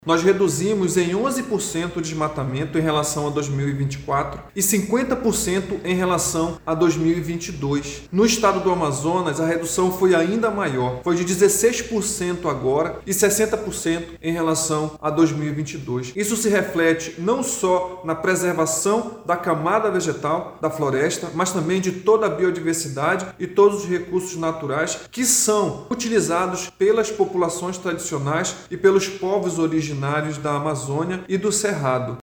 A boa notícia veio mesmo com o aumento das queimadas registrado em 2025, explica o Superintendente do IBAMA no Amazonas, Joel Araújo.
sonora-1-joel.mp3